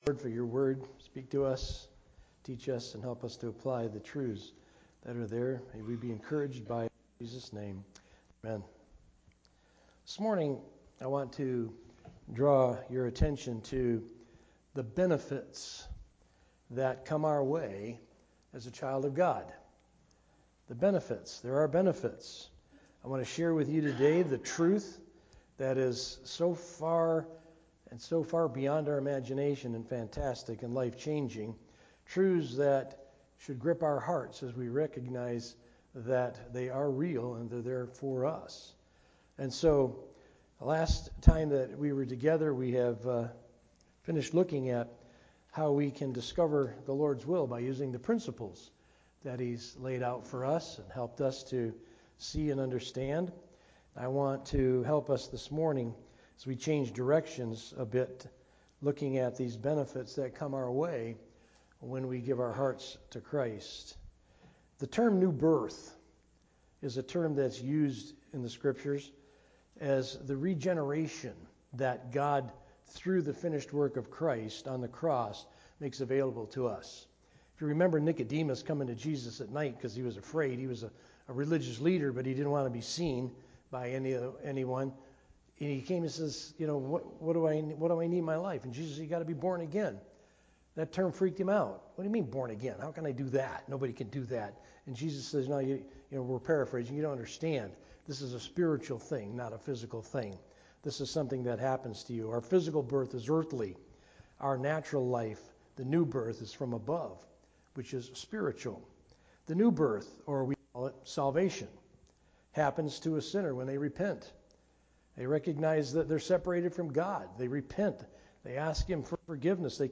From Series: "Sunday Morning - 11:00"
Related Topics: Sermon